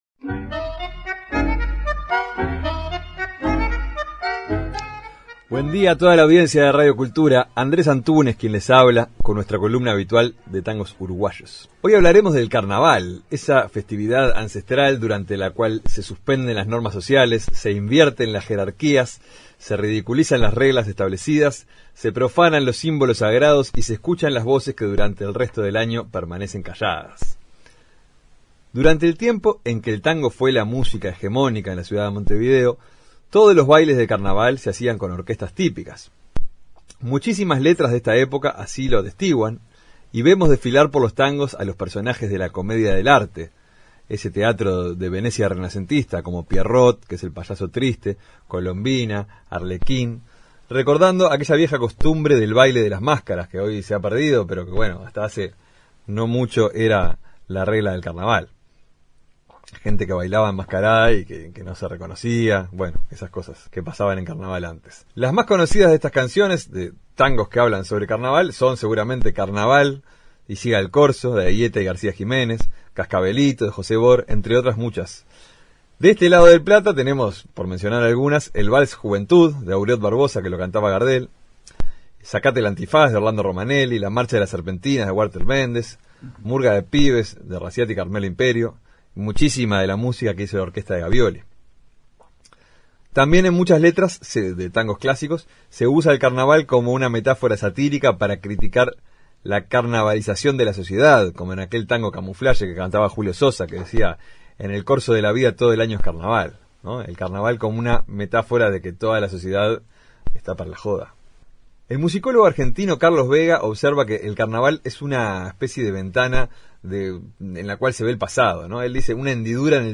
Columna quincenal